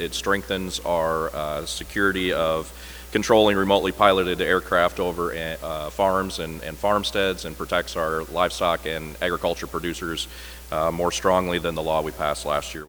Representative Derek Wulf, a farmer from Hudson, says it's an important expansion of last year's law.